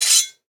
sword-unsheathe.wav